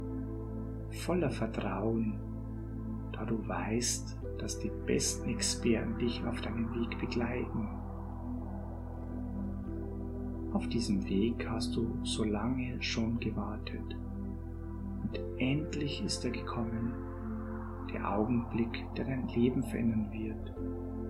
G2007-Erstsitzung-Das-Hypnotisches-Magenband-in-der-Spezialklinik-maennliche-Stimme-Hoerprobe.mp3